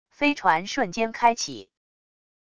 飞船瞬间开启wav音频